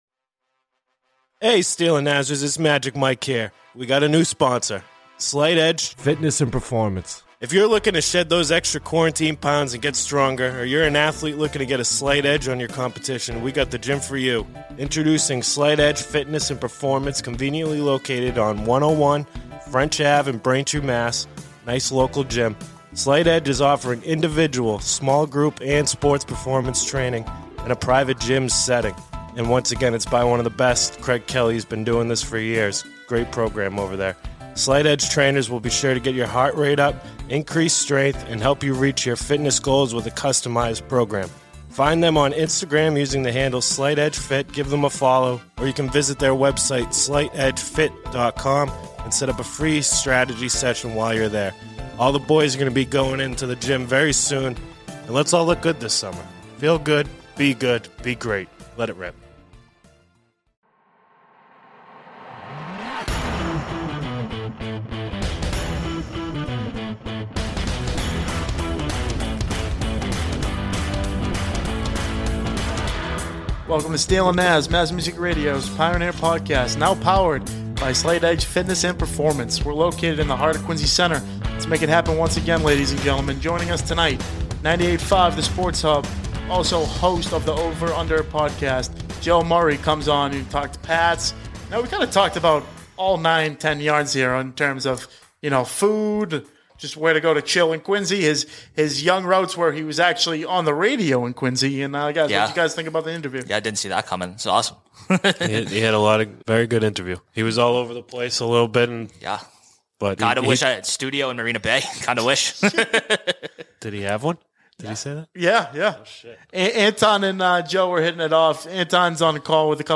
at the Mass Music Radio Station in Quincy, Massachusetts.